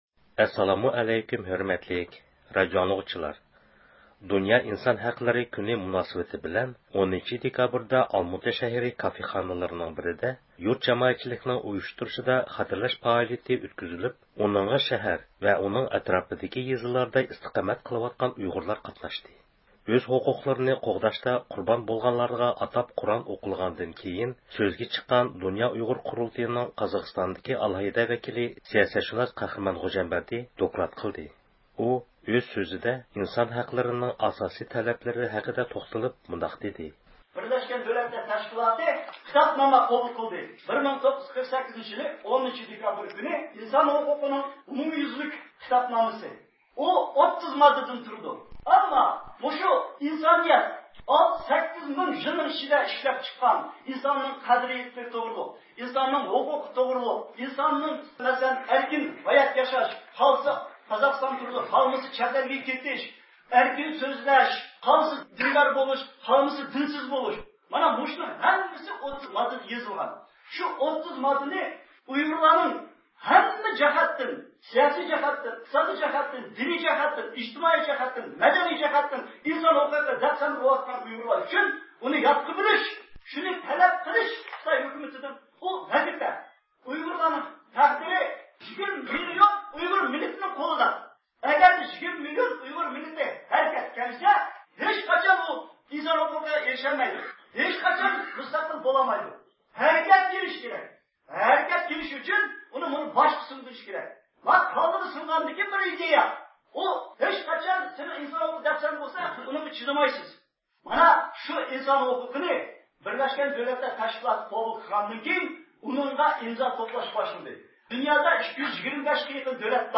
دۇنيا ئىنسان ھەقلىرى كۈنى مۇناسىۋىتى بىلەن 10-دېكابىر ئالماتا شەھىرى كافېخانىلىرىنىڭ بىرىدە يۇرت-جامائەتچىلىكنىڭ ئۇيۇشتۇرۇشىدا خاتىرىلەش پائالىيىتى ئۆتكۈزۈلۈپ، ئۇنىڭغا شەھەر ۋە ئۇنىڭ ئەتراپىدىكى يېزىلاردا ئىستىقامەت قىلىۋاتقان ئۇيغۇرلار قاتناشتى.